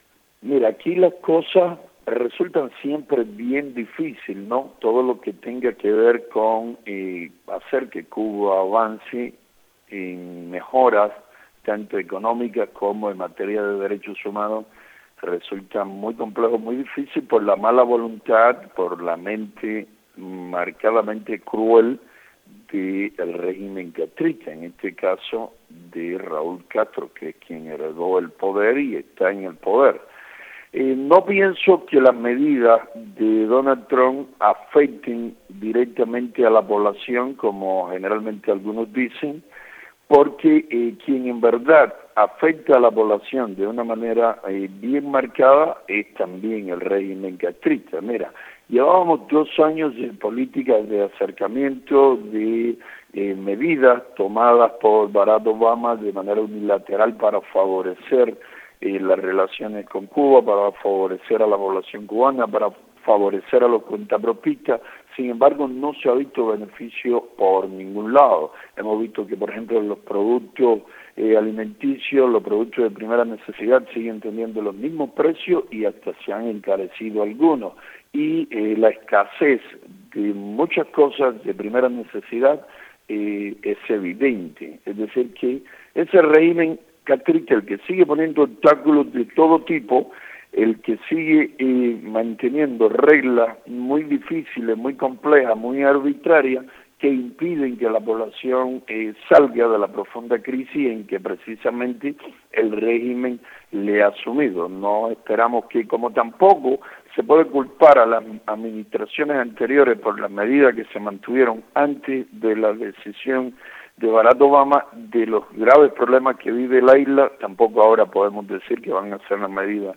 Declaraciones de José Daniel Ferrer a Radio Martí.